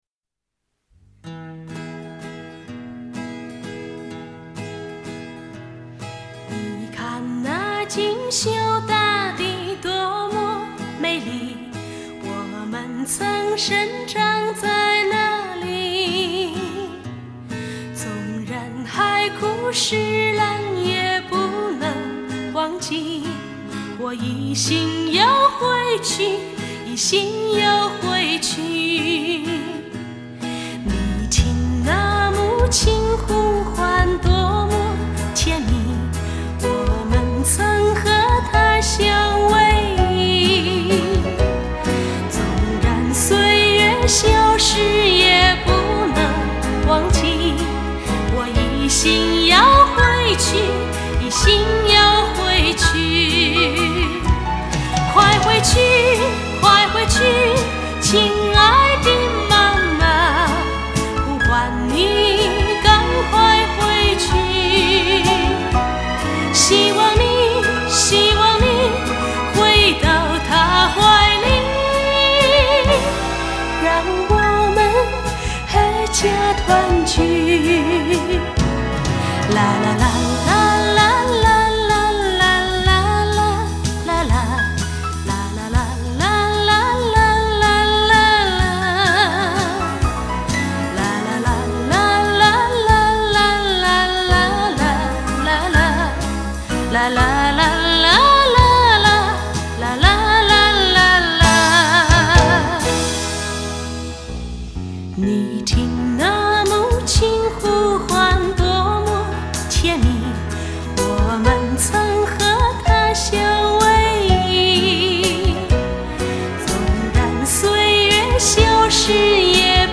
音质还行，里面或许有不少老歌粉耳熟能详的歌星，但我不太熟悉。